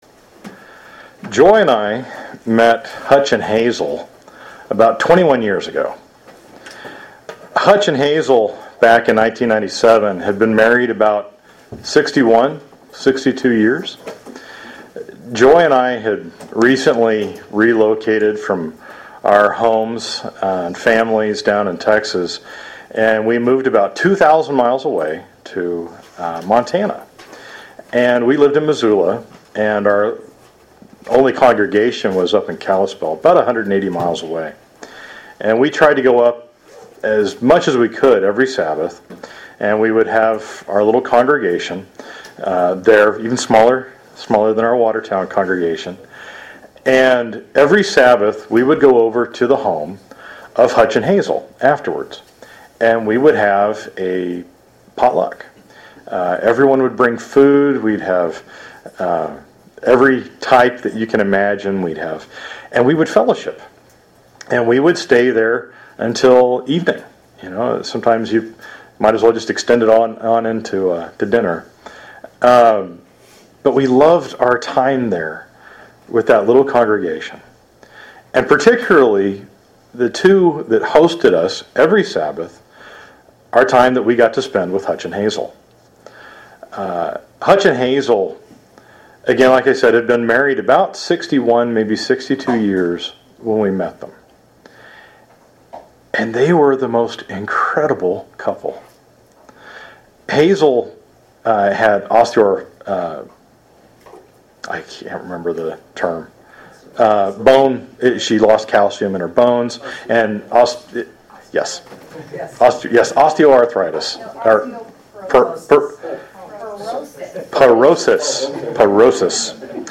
Sermons
Given in Sioux Falls, SD Watertown, SD